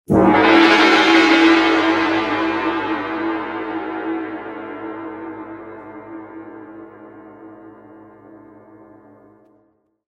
「銅鑼（どら）」、それは中国の伝統的な楽器です。